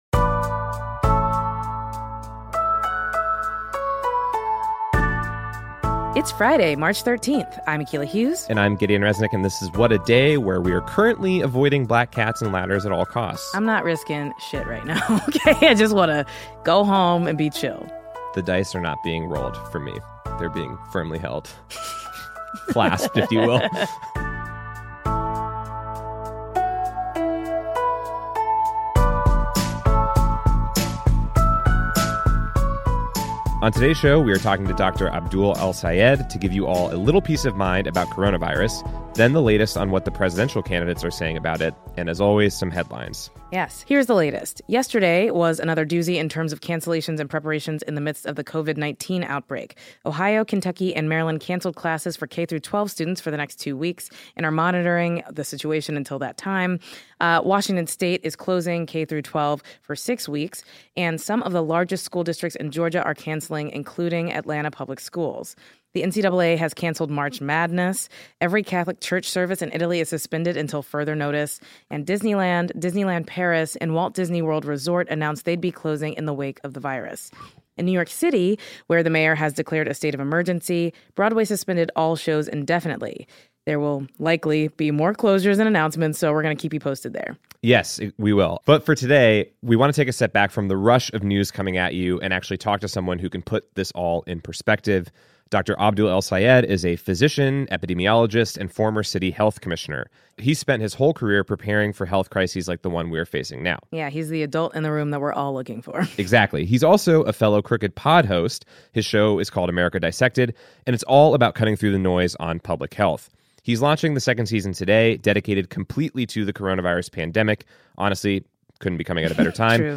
Physician and former Detroit health commissioner Abdul El-Sayed comes on the show to discuss Covid-19. We ask him about the government response, how to avoid overloading our healthcare system, and what we can take from the way other countries have responded.